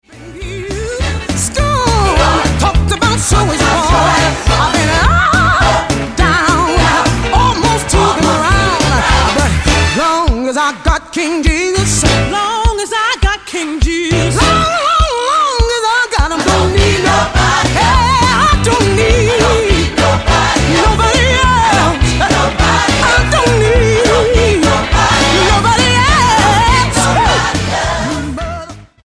STYLE: Gospel
foot stomping, hand clapping vibe